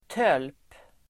Uttal: [töl:p]